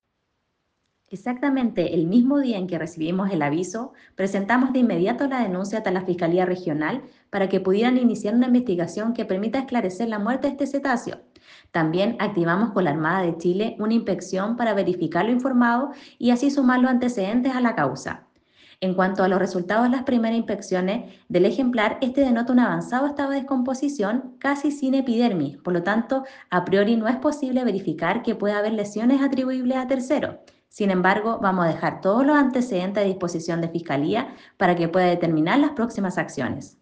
Daniela Leiva Directora Regional de SERNAPESCA Region de Aysen